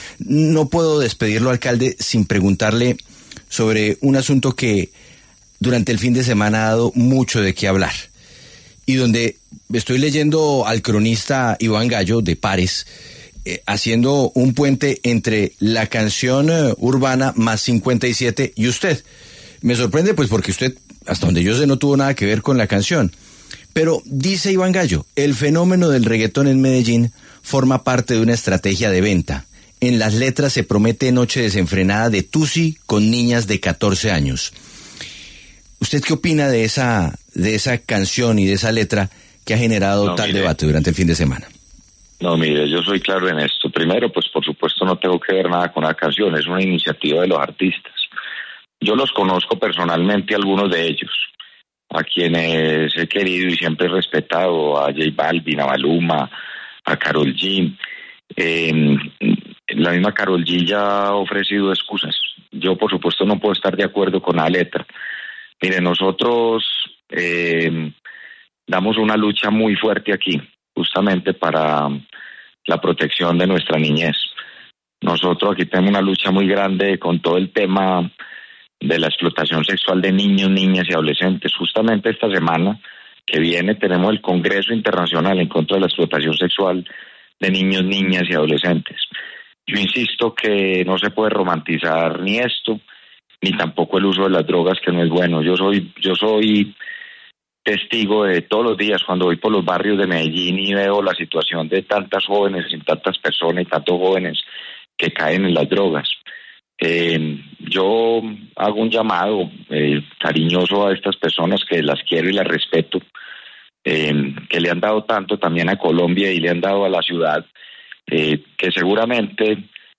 En entrevista con La W, Gutiérrez afirmó que no apoya este tipo de mensajes, los cuales considera contrarios a la lucha que lleva a cabo la administración local para proteger a la niñez y combatir la explotación sexual infantil.